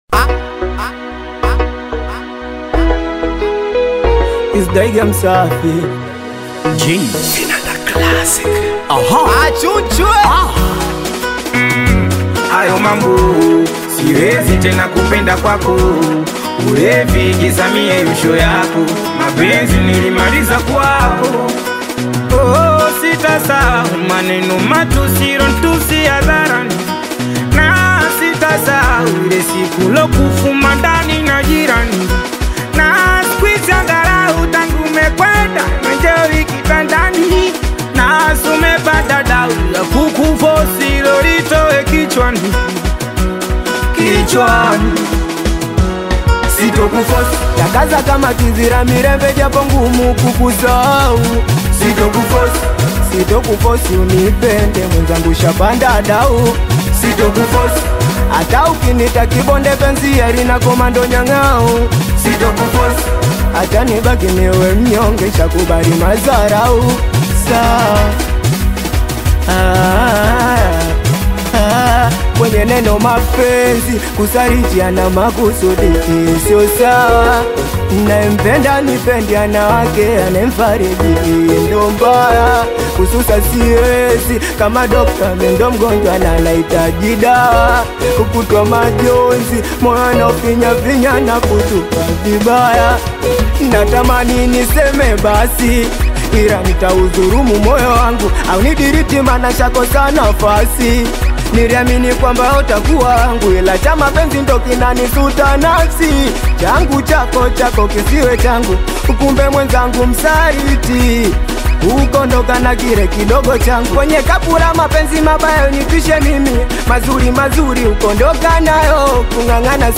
is a high-tempo Singeli song
use sharp lyrics and aggressive flows